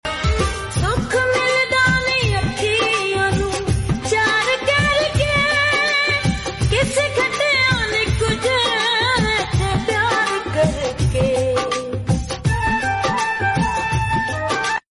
zzzzzz sound effects free download